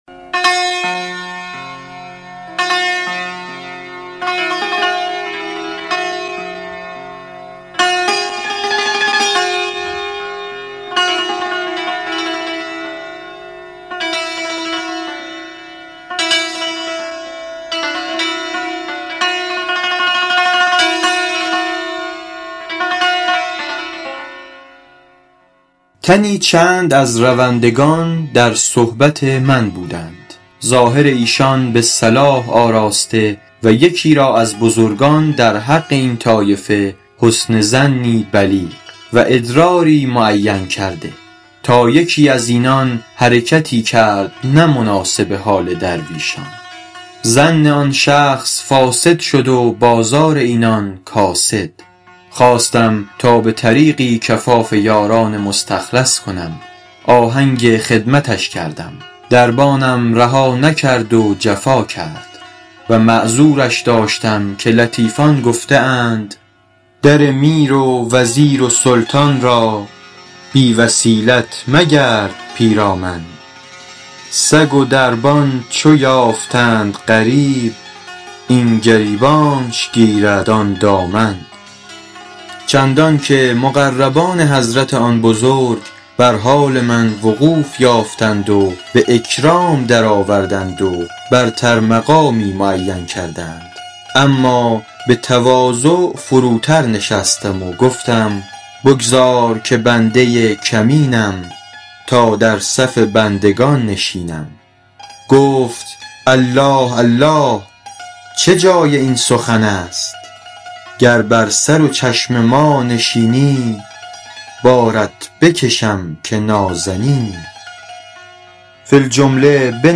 حکایت شمارهٔ ۱۷ به خوانش